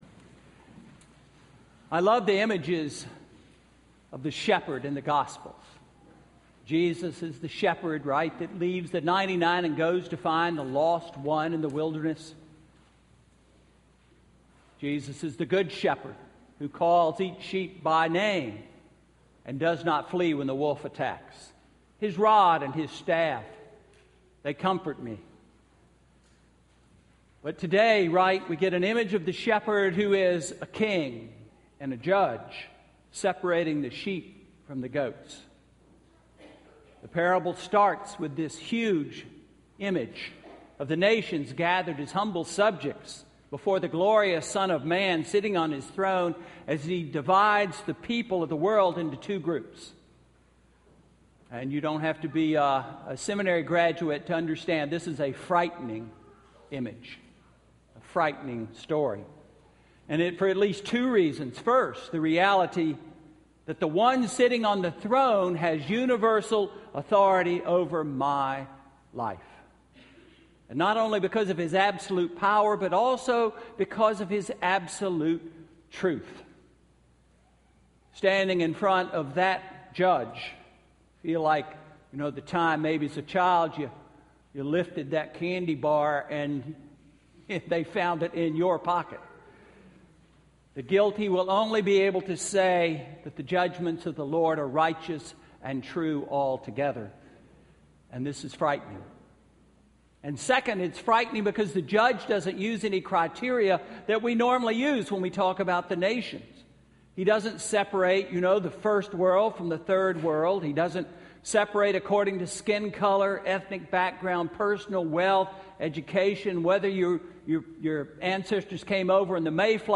Sermon–November 23, 2014